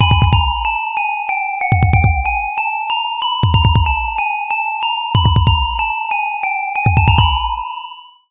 描述：迫在眉睫的声音
Tag: 未来派 电子 报警